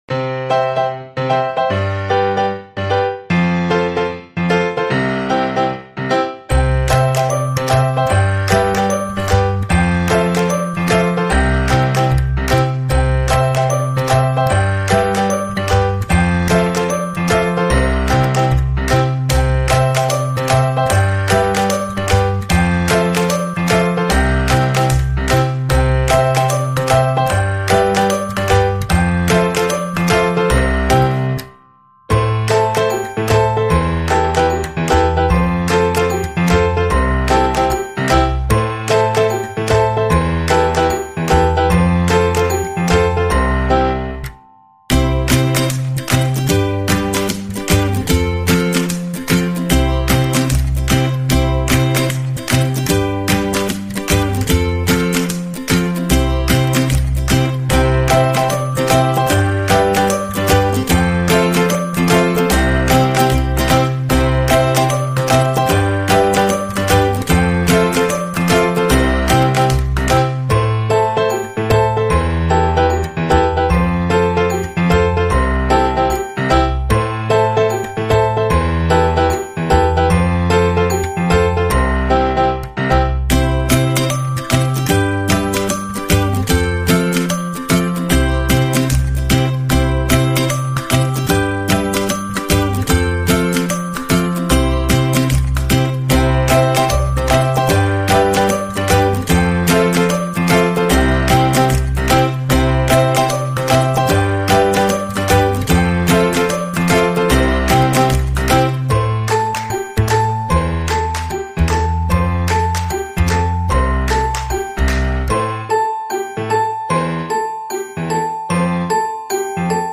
KGivKgR0WRA_y2matecom-musica-instrumental-alegre-para-ninos-pequenos-preescolar-y-jardin-3DcHK2O7.mp3